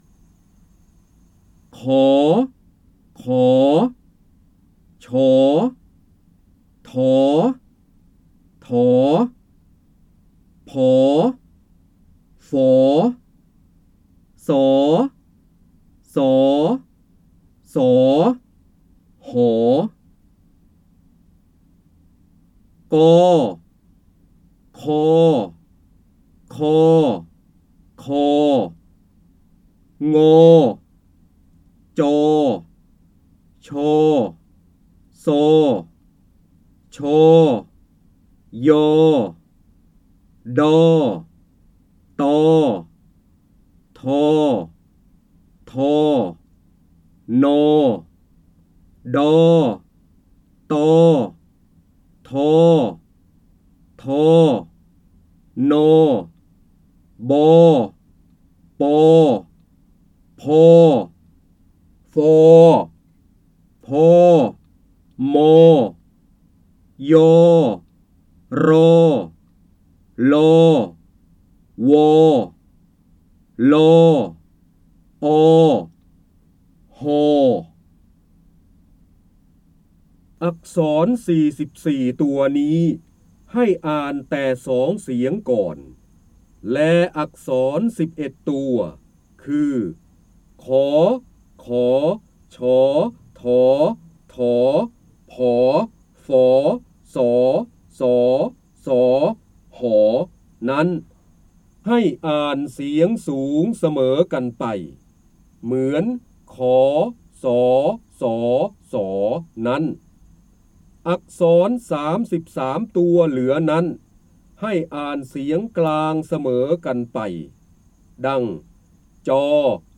เสียงบรรยายจากหนังสือ จินดามณี (พระเจ้าบรมโกศ) ขฃ
คำสำคัญ : การอ่านออกเสียง, ร้อยกรอง, พระโหราธิบดี, ร้อยแก้ว, พระเจ้าบรมโกศ, จินดามณี